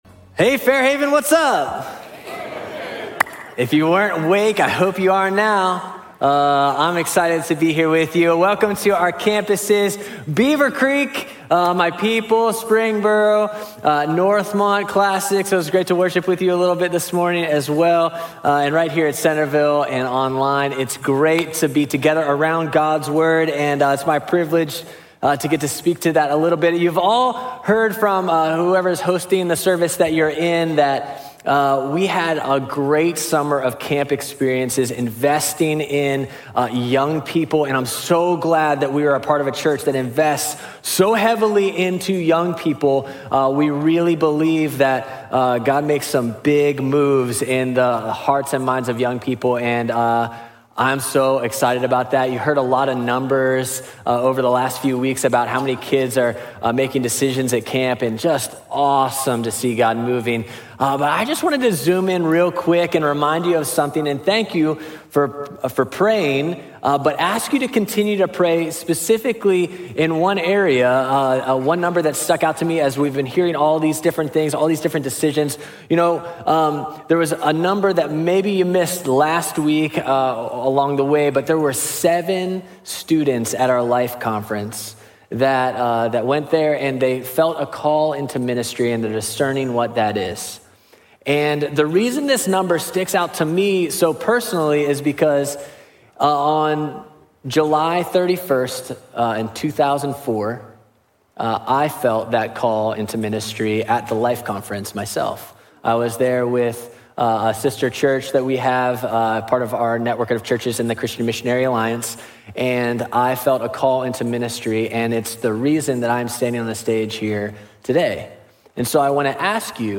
The-Greatest-Promise_SERMON.mp3